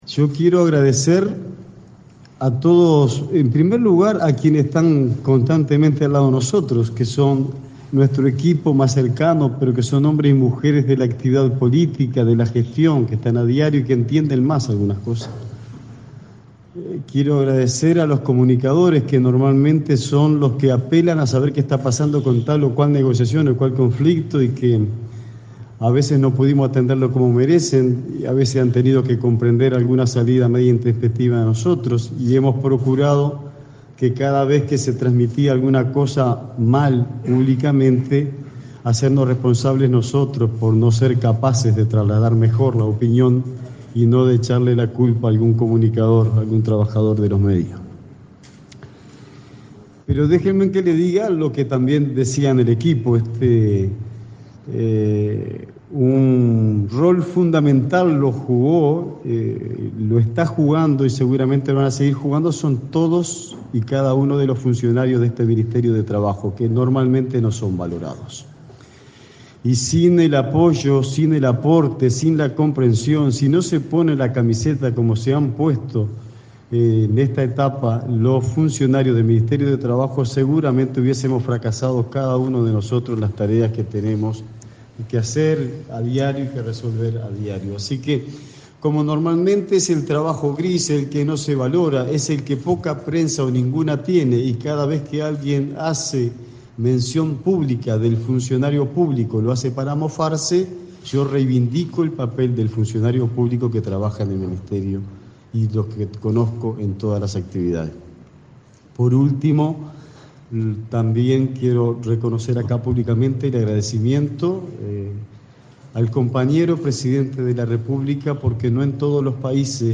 El saliente director de Trabajo, Juan Castillo, agradeció la propuesta del presidente Tabaré Vázquez en 2015 y reconoció la labor silenciosa de los funcionarios públicos de esa cartera, en su despedida en el Ministerio de Trabajo.